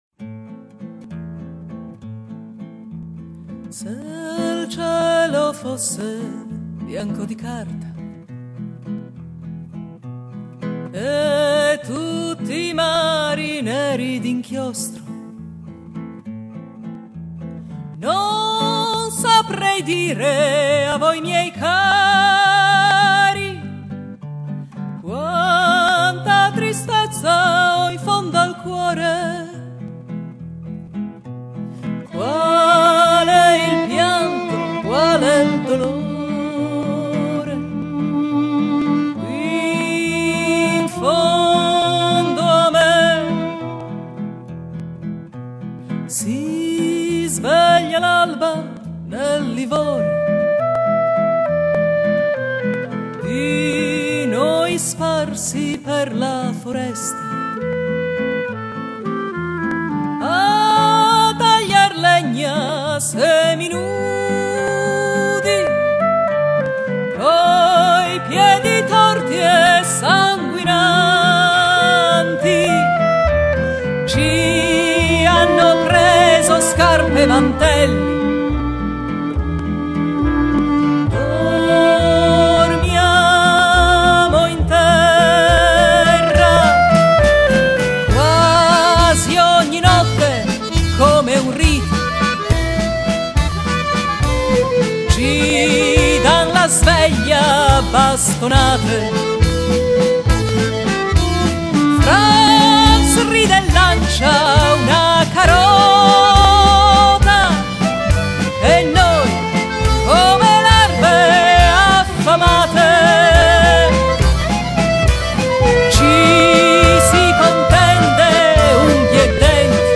Se il cielo fosse bianco di carta is an example of a partisan folk song.